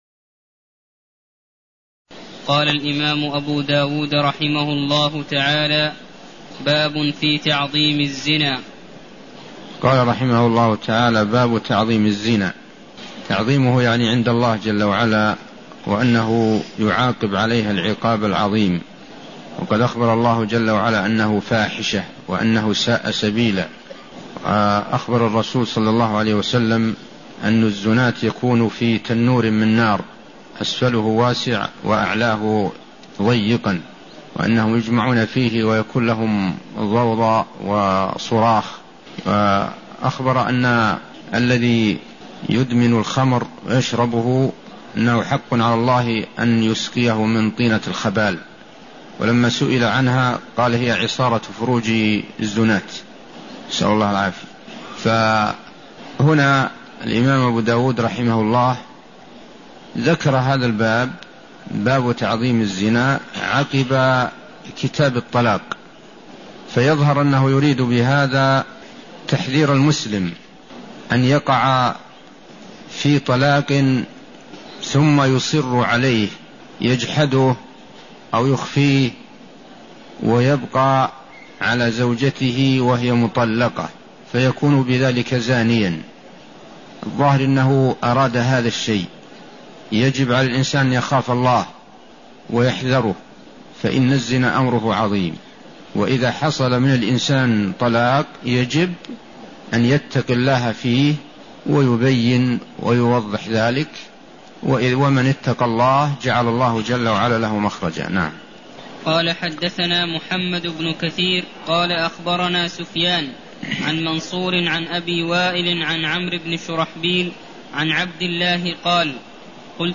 المكان: المسجد النبوي الشيخ: عبدالله الغنيمان عبدالله الغنيمان كتاب الطلاق باب في تعظيم الزنا (12)